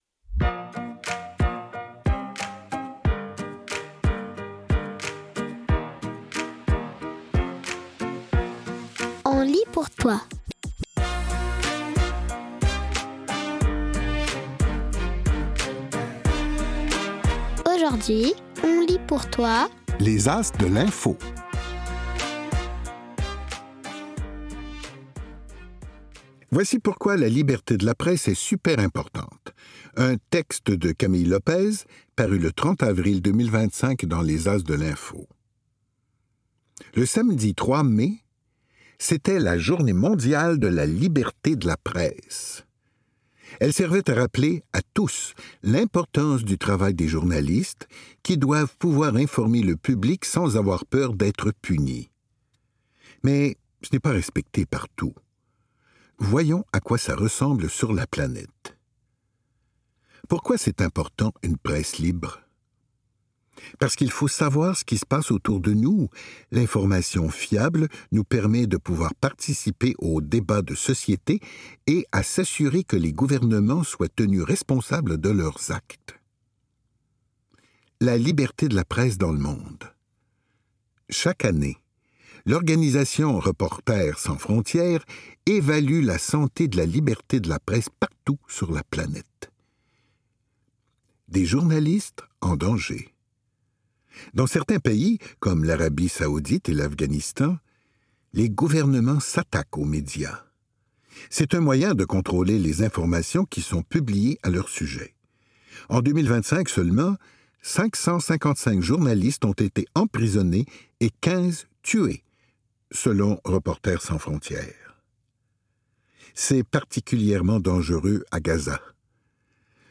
Images et mots, à voix haute!